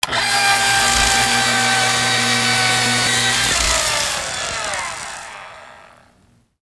laser.wav